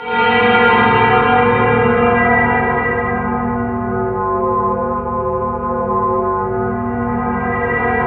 Index of /90_sSampleCDs/E-MU Producer Series Vol. 3 – Hollywood Sound Effects/Science Fiction/Brainstem